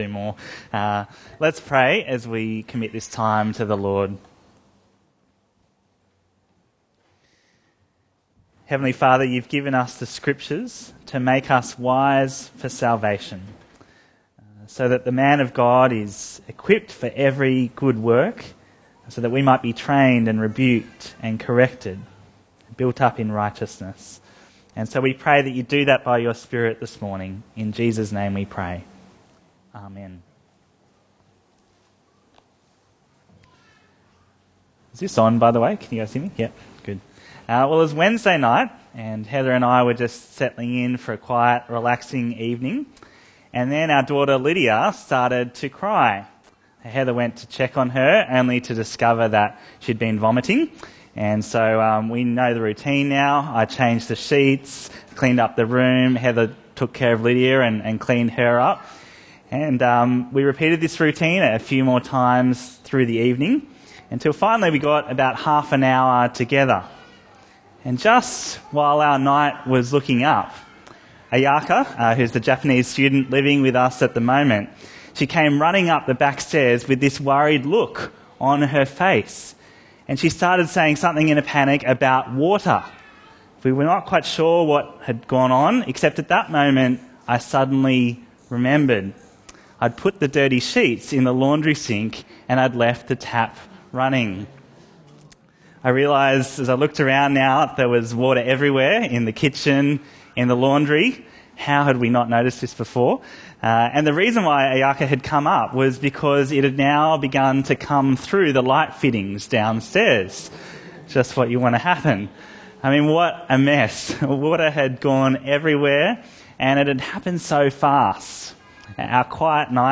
Bible Talks Bible Reading: Genesis 4-5